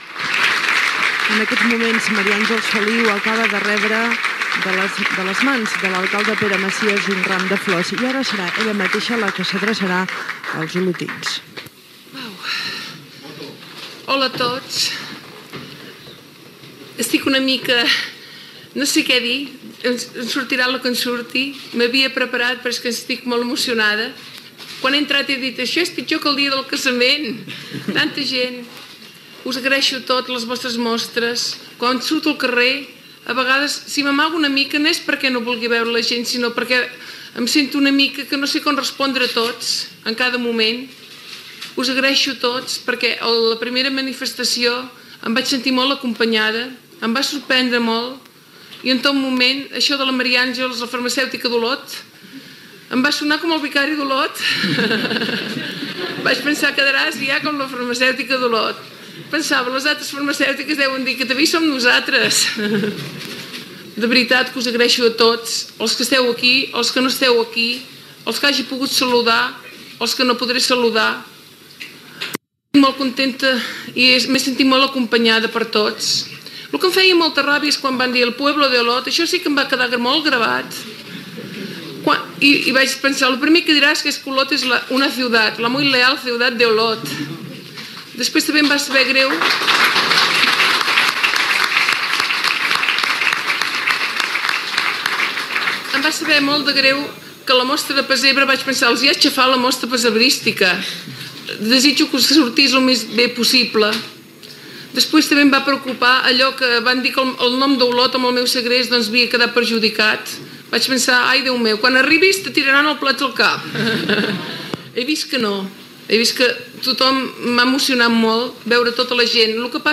Recepció a l'Ajuntament d'Olot de Maria Àngels Feliu després del seu alliberament del llarg segrest que va patir.
Informatiu